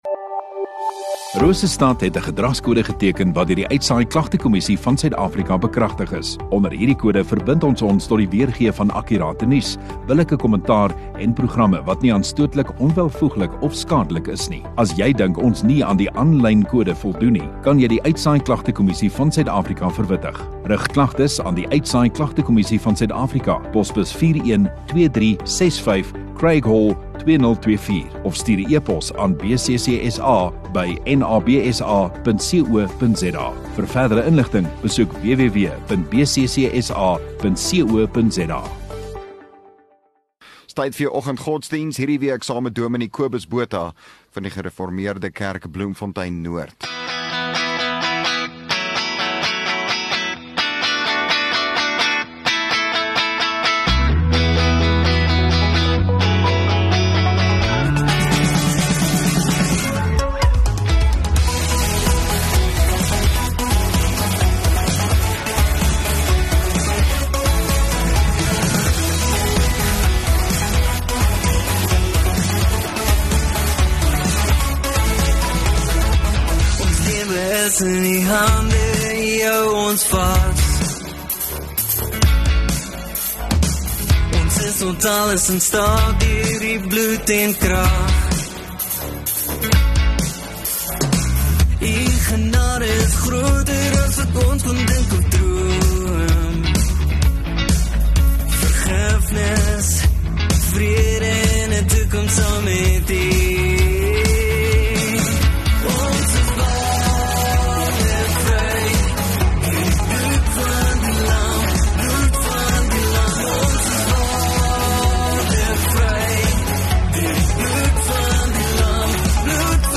13 May Dinsdag Oggenddiens